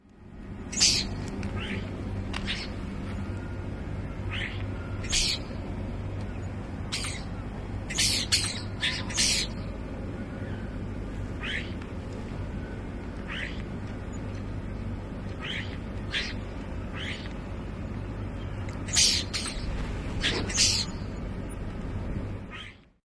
描述：椋鸟，机械，回声，Grundfjordur，冰岛，鸟鸣，风